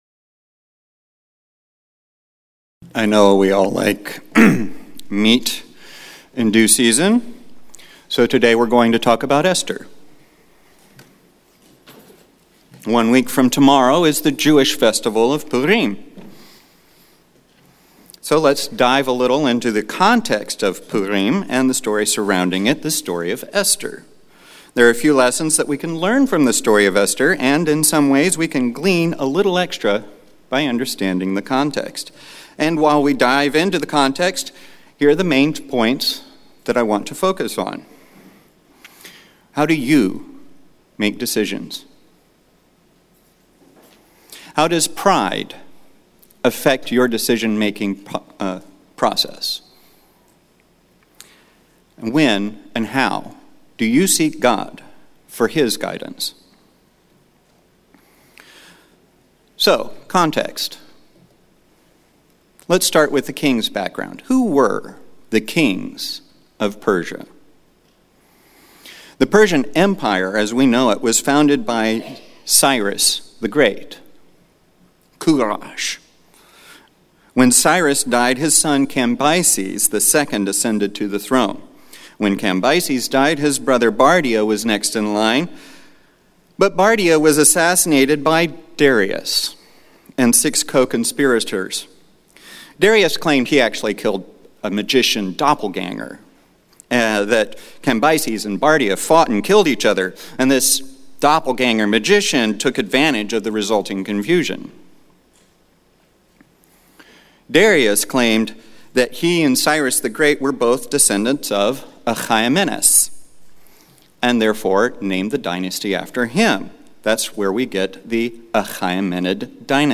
Sermon
Given in Houston, TX